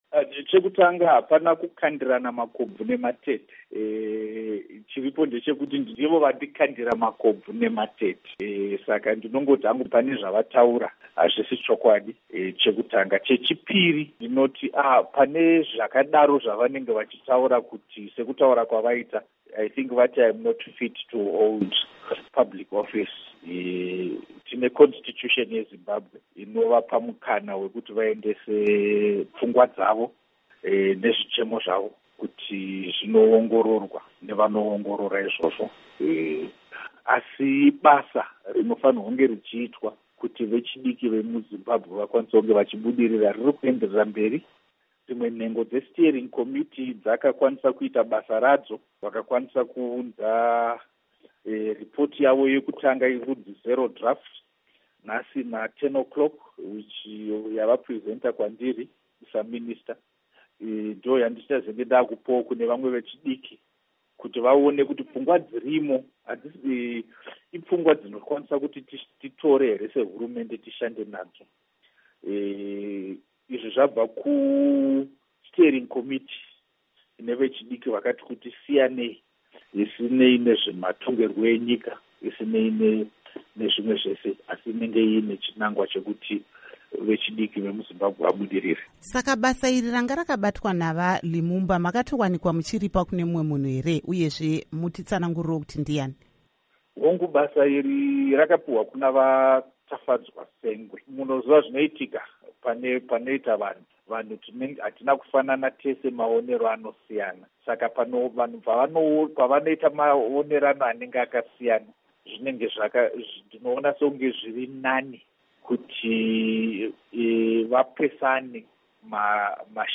Hurukuro naVaPatrick Zhuwao